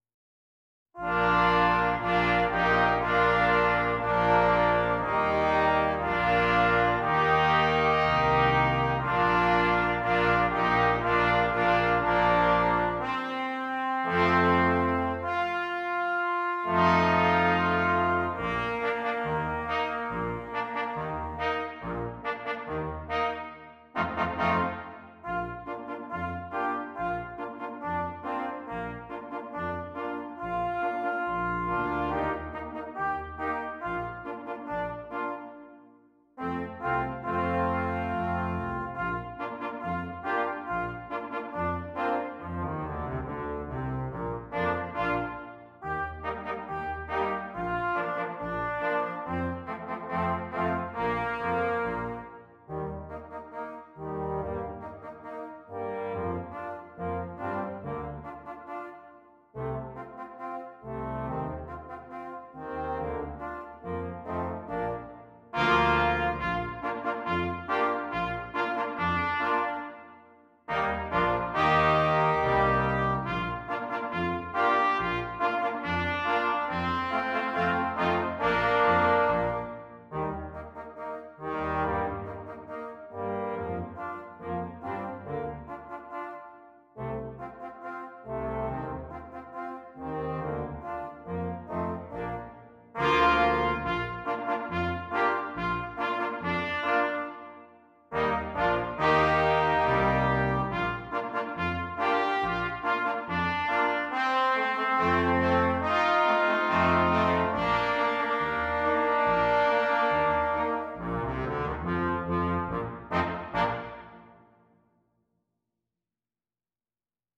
Christmas
Brass Quintet